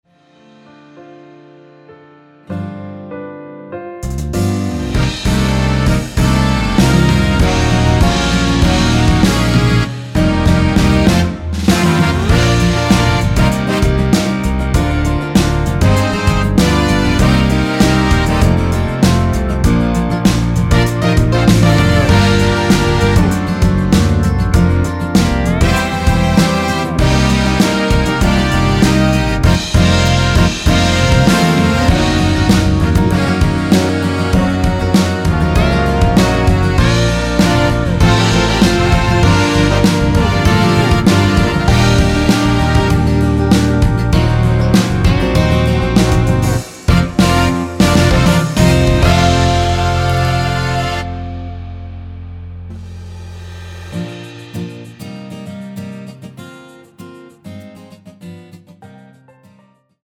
원키에서(-2)내린(1절+후렴)으로 진행되는 MR입니다.
Bb
앞부분30초, 뒷부분30초씩 편집해서 올려 드리고 있습니다.
중간에 음이 끈어지고 다시 나오는 이유는